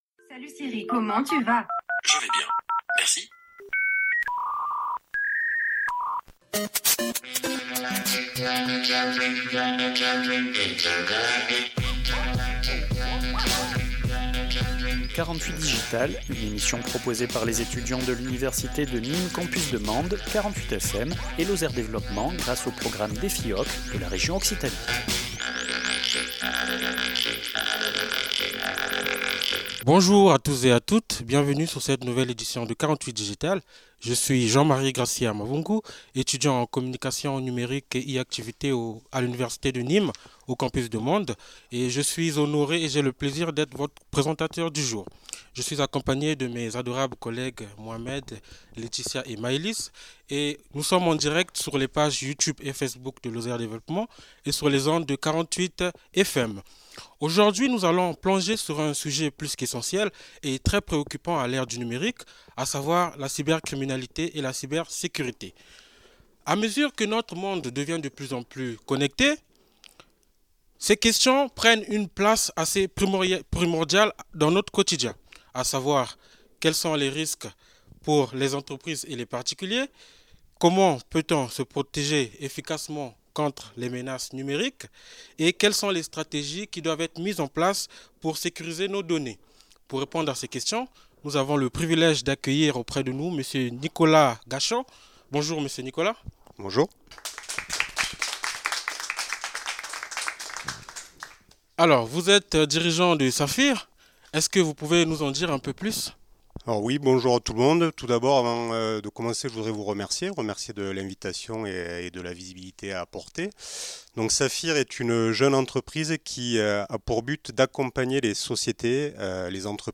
Une émission proposée par 48FM, l’Université de Nîmes antenne de Mende et Lozère développement